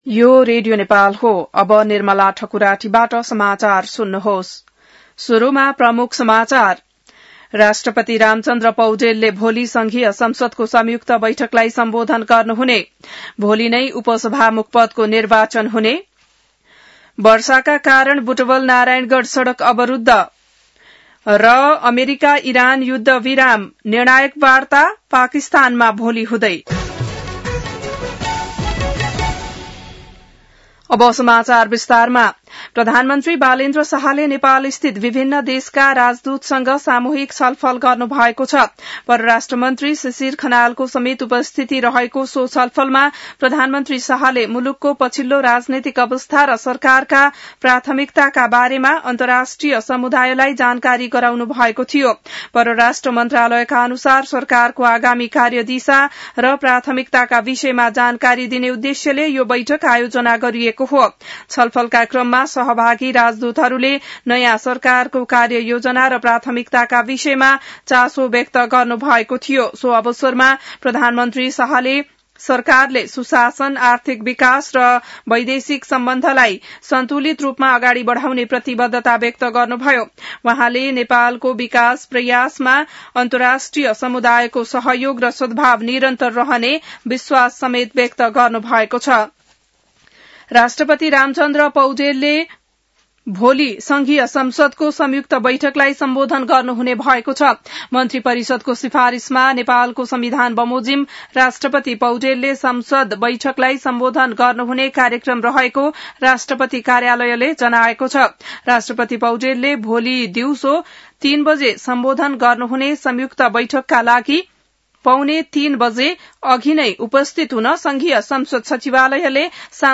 बिहान ९ बजेको नेपाली समाचार : २६ चैत , २०८२